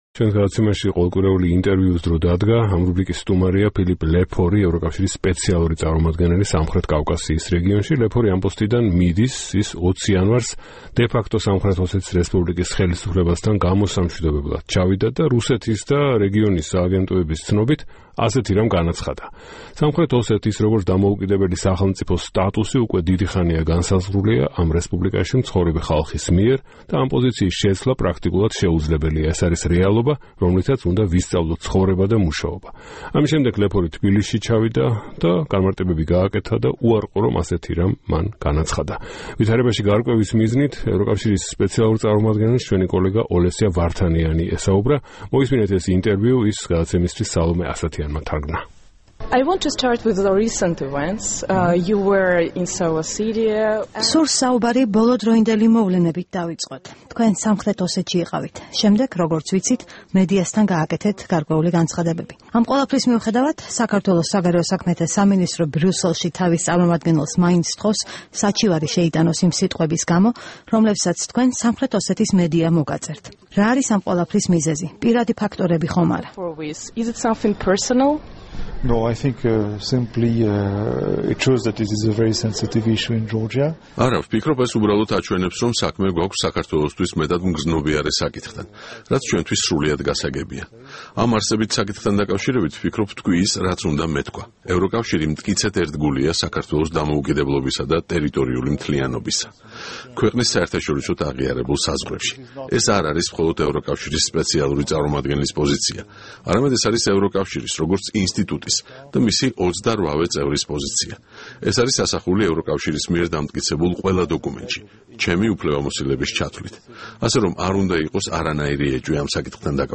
საუბარი ფილიპ ლეფორთან